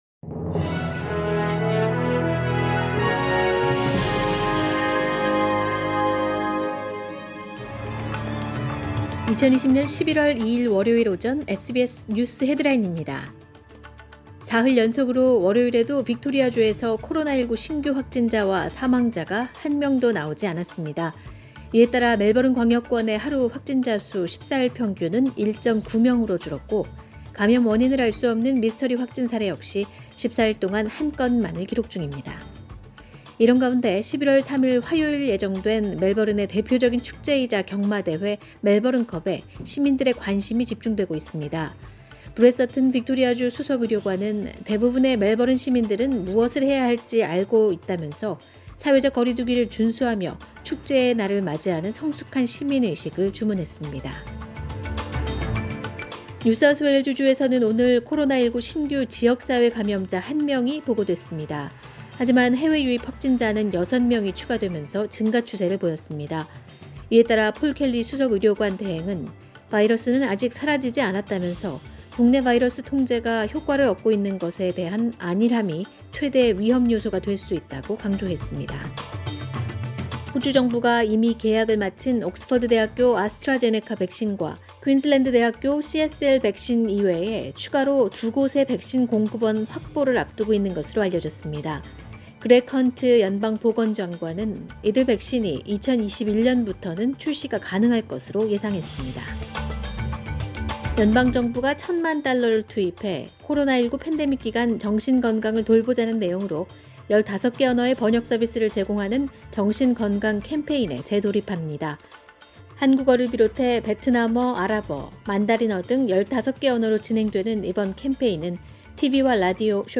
2020년 11월 2일 월요일 오전의 SBS 뉴스 헤드라인입니다.